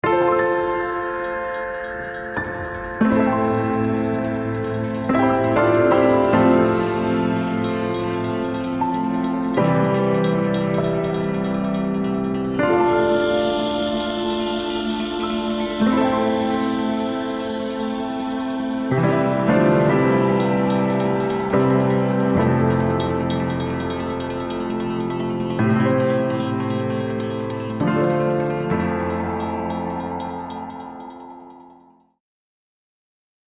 Style: Ambient